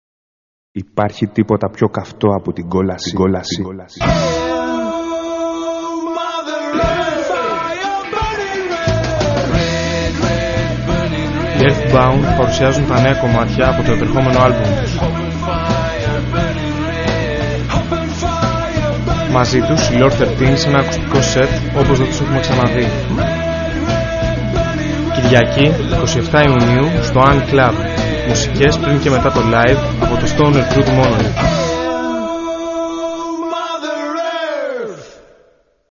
Radio Spot